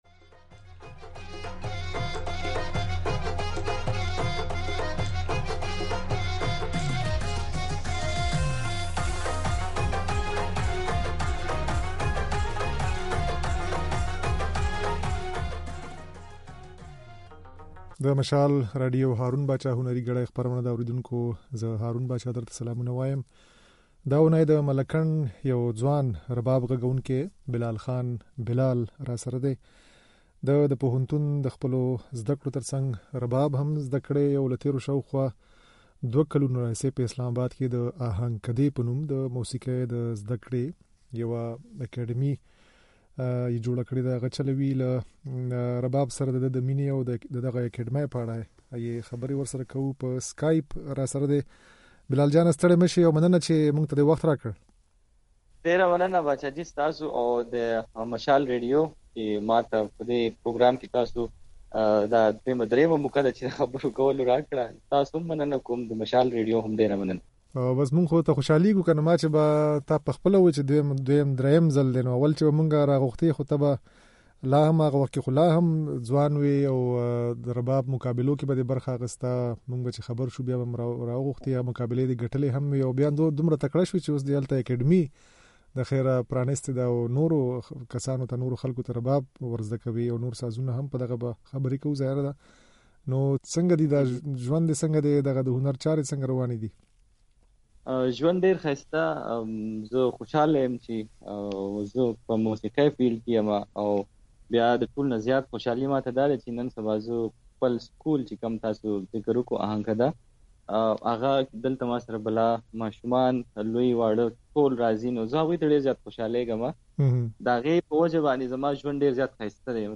خبرې او په رباب غږولې ځينې نغمې يې د غږ په ځای کې اورېدای شئ